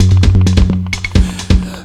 2 Foyer Bass Roll.wav